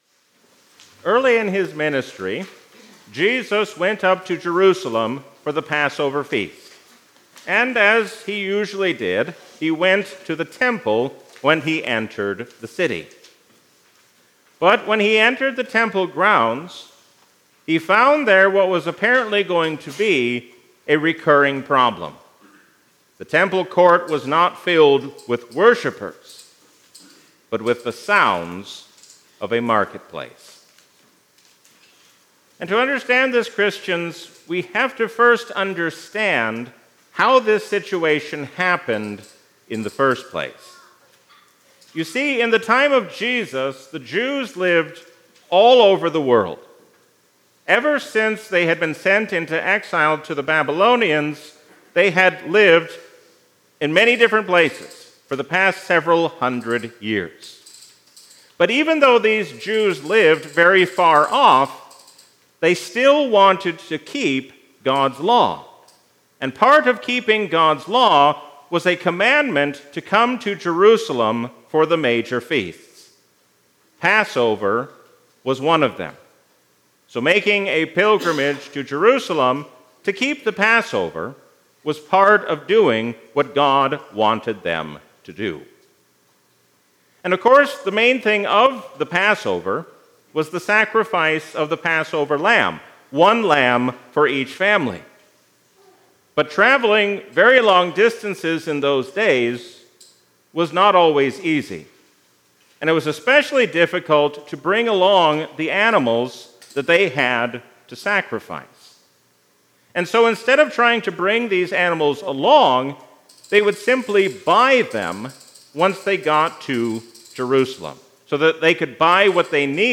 A sermon from the season "Lent 2026." We can only know who Jesus truly is when we see Him hanging on the cross.